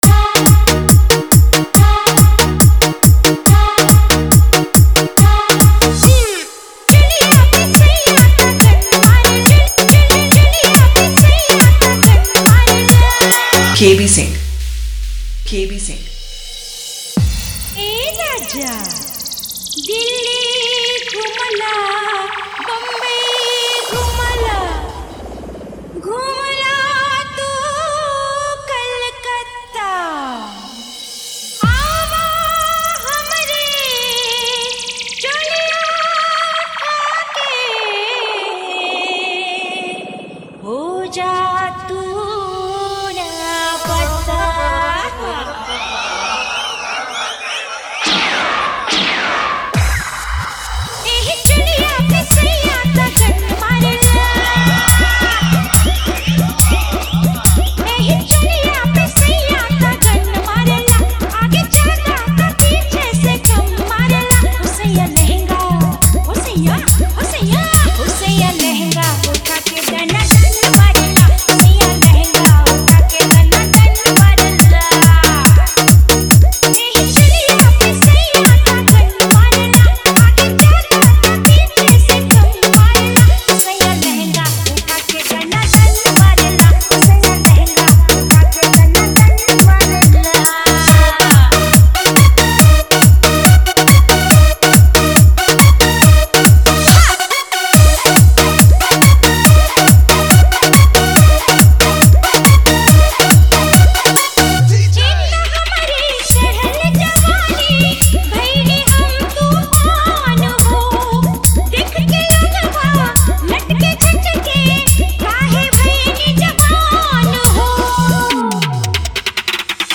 Party Song Dj Remix